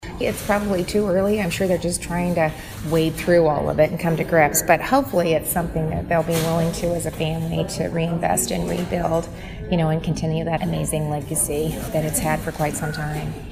Reynolds made her comments Thursday while visiting several communities in the listening area.